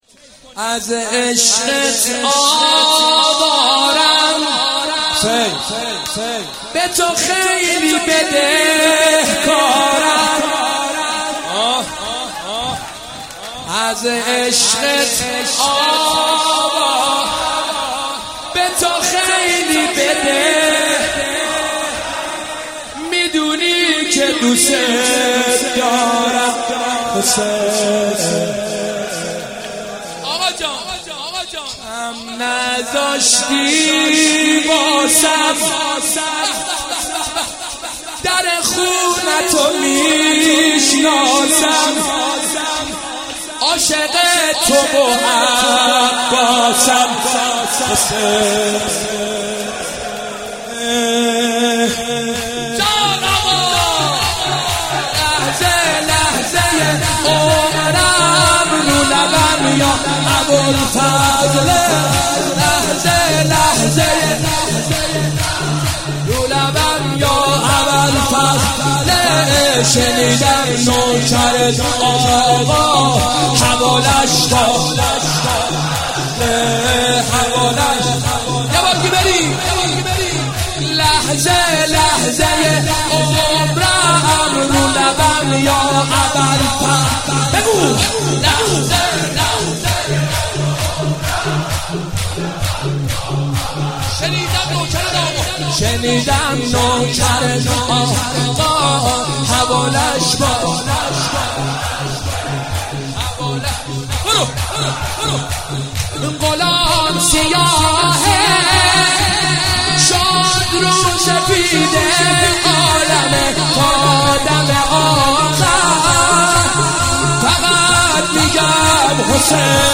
(شور جدید)